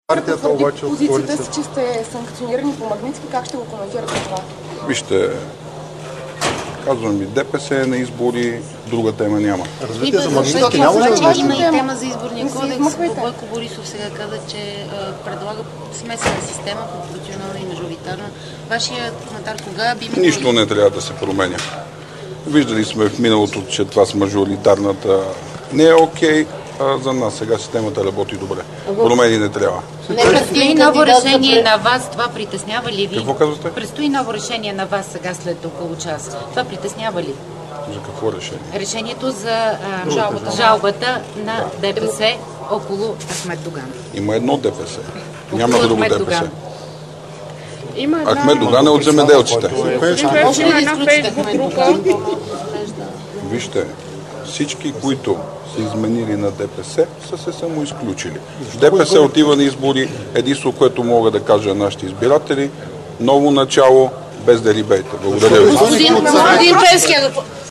10.10 - Брифинг на Станислав Балабанов от ИТН - директно от мястото на събитието (Народното събрание)